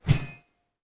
sword_woosh_1.wav